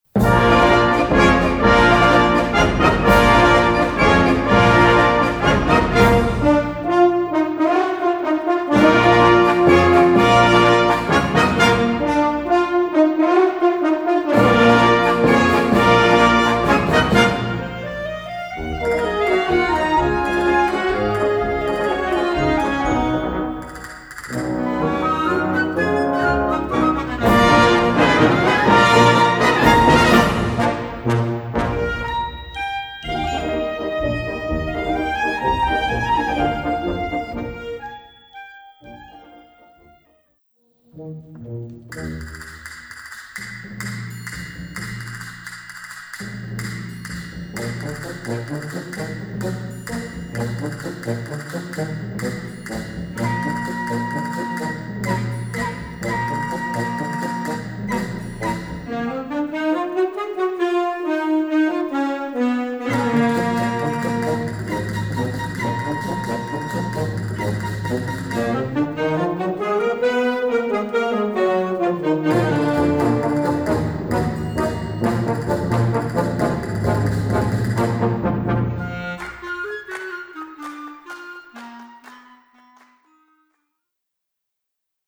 輸入吹奏楽オリジナル作品